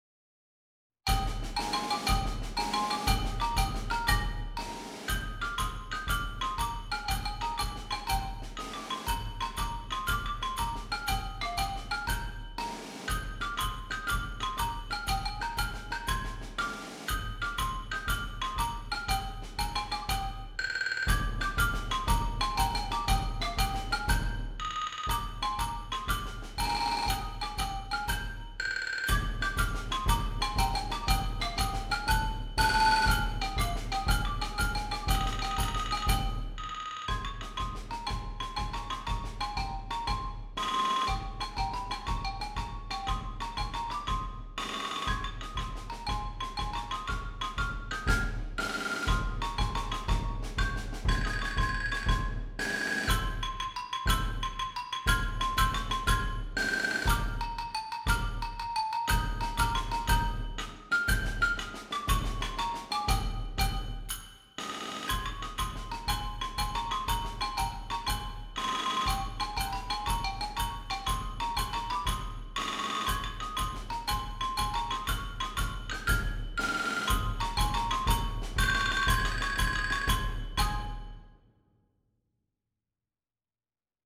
Percussion Ensemble